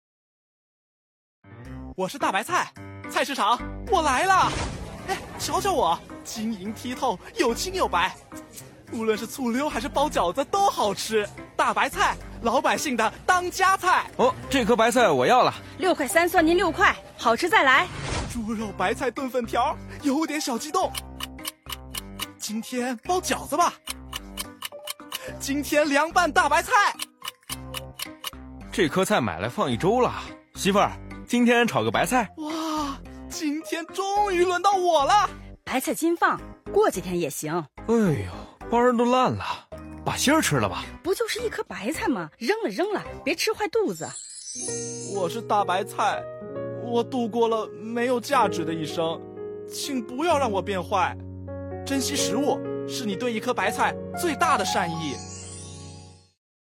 广告文案作品《请不要让我变坏》和《“双碳”就在我身边》均已被总台广播公益广告制作中心制作成为广播公益广告，正在中央广播电台各频道及央广网播出，这也是中央广播电视总台广播公益广告制作中心举办的2022年广播公益广告优秀文案有奖征集活动获奖成果展示的一部分。